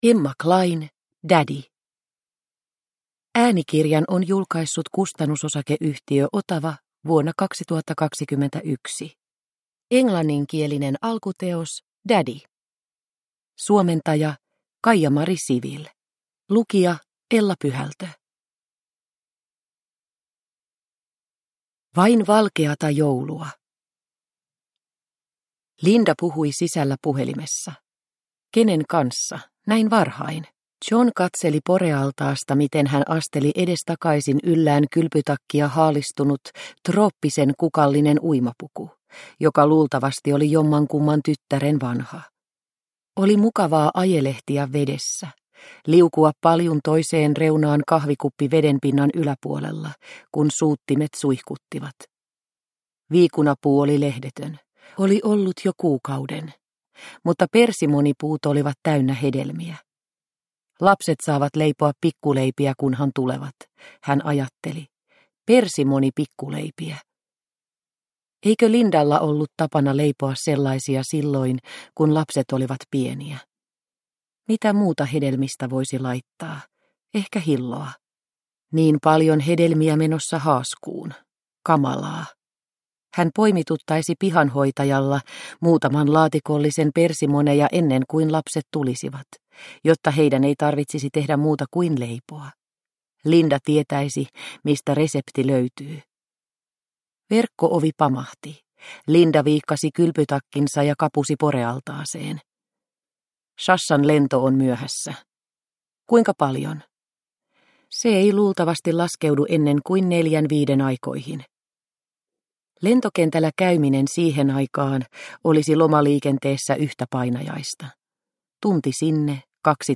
Daddy – Ljudbok – Laddas ner